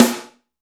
34 SNARE 3-R.wav